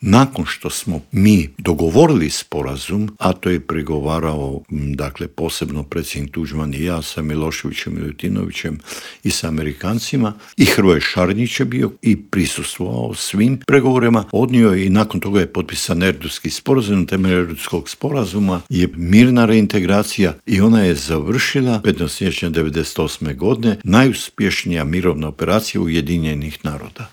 ZAGREB - Uoči 34. godišnjice međunarodnog priznanja Hrvatske i 28. obljetnice završetka mirne reintegracije hrvatskog Podunavlja u Intervju Media servisa ugostili smo bivšeg ministra vanjskih poslova Matu Granića, koji nam je opisao kako su izgledali pregovori i što je sve prethodilo tom 15. siječnju 1992. godine.